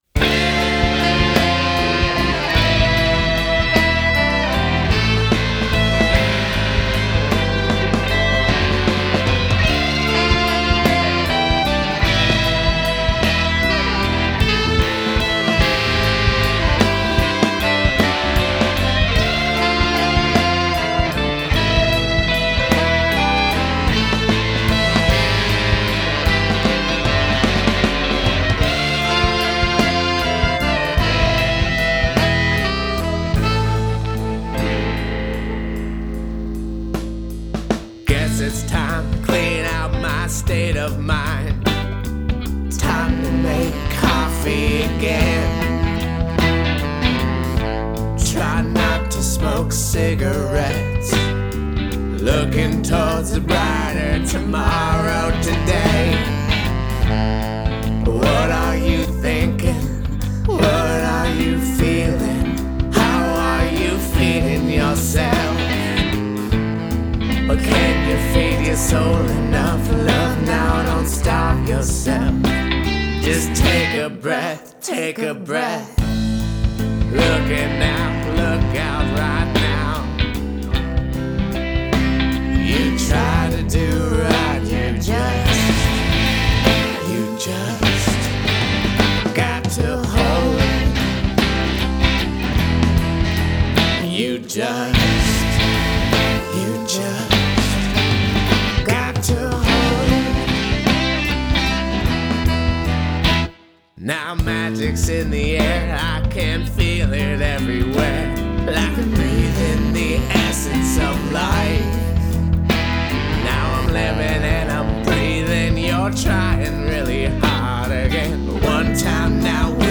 RabbitRoughMix.wav